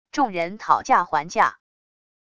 众人讨价还价wav音频